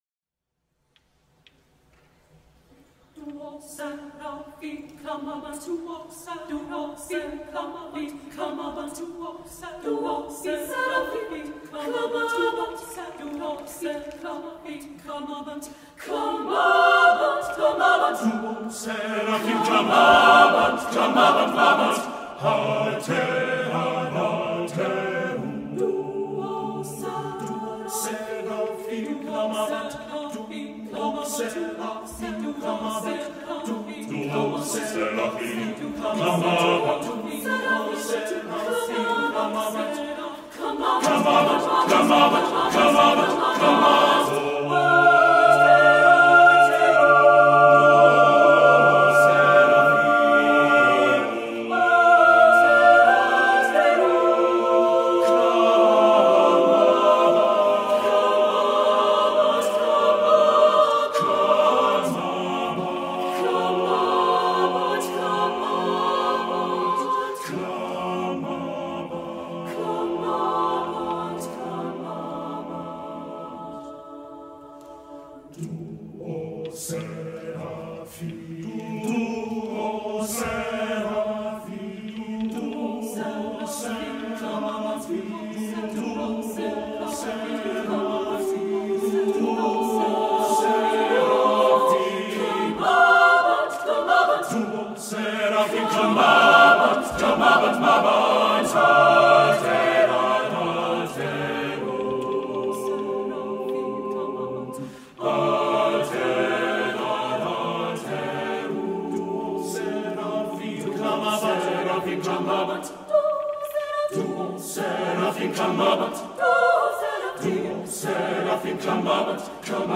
Voicing: "SSAATB"